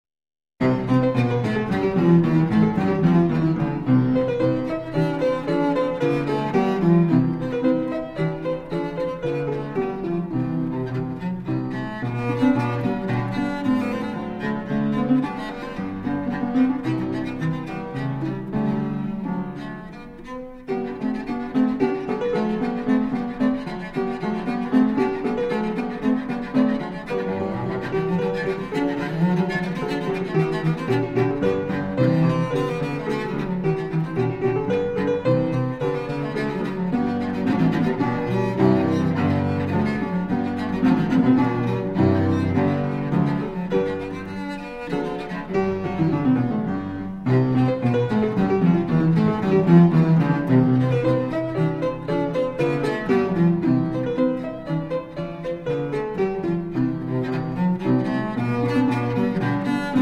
World premiere recording of the accompanied cello sonatas
Harpsichord
Classical Cello